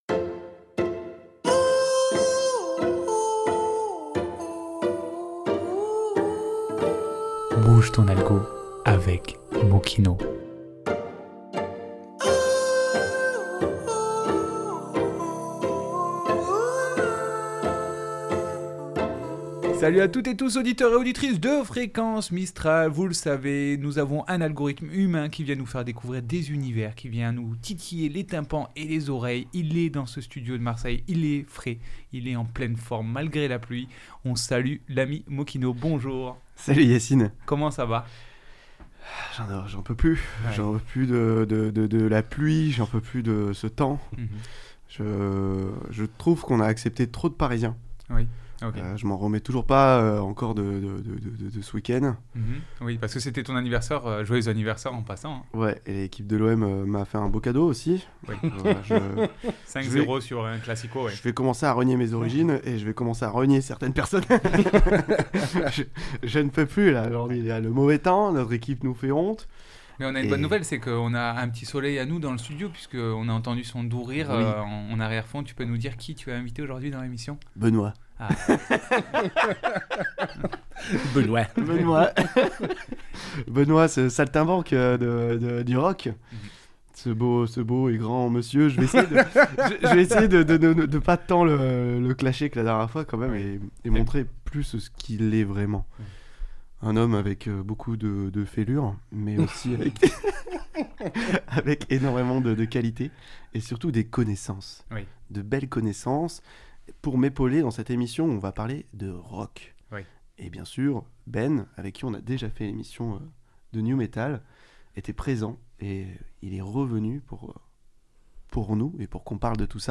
émissions musicale spéciale